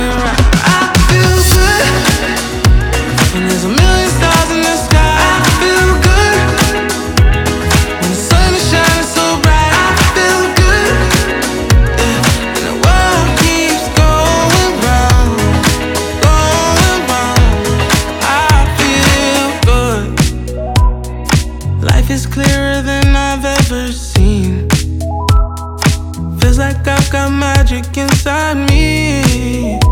Жанр: Соул / R&b / Русские